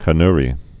(kə-nrē)